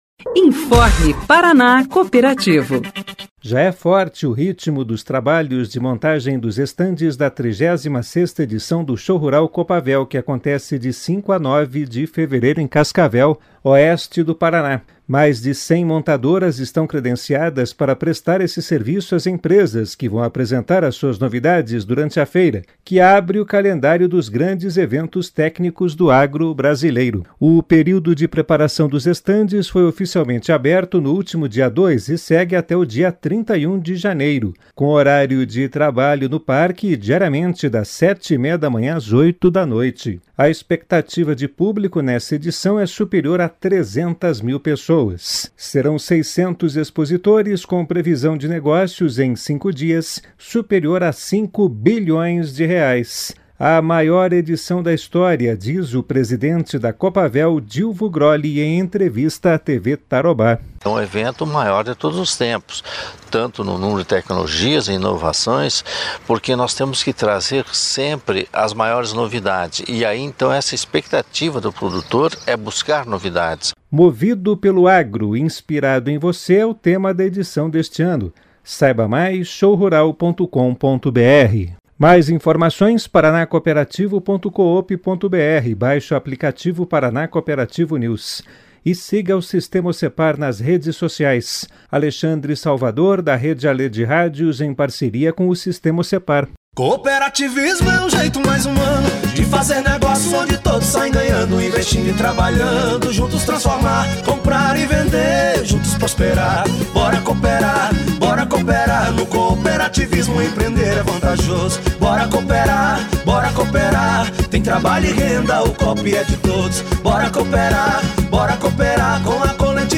Notícias Rádio Paraná Cooperativo